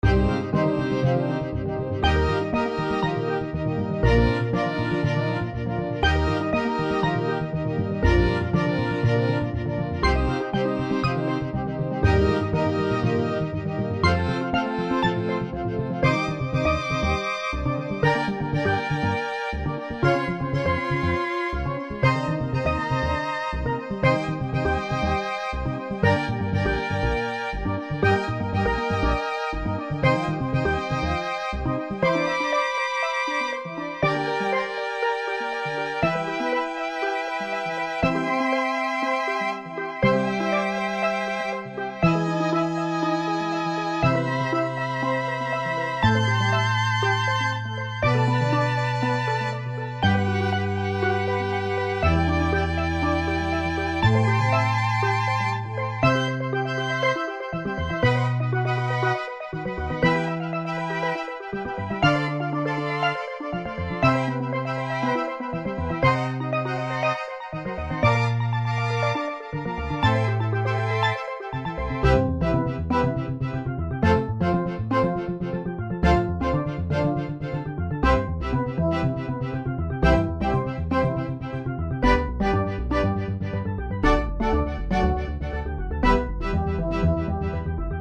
BGM
スティールドラム、アコースティックベース、チェロ